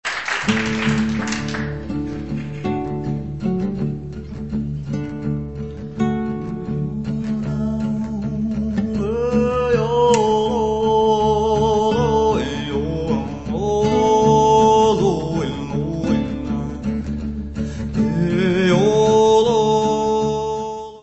guitarra, caja
voz e lávium
guitarra, percussão e voz
tabla e voz.
Music Category/Genre:  World and Traditional Music